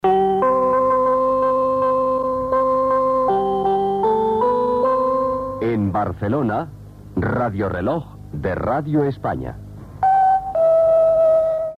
Indicatiu i toc de l'hora, entrada i sortida butlletí de cada 15 minuts.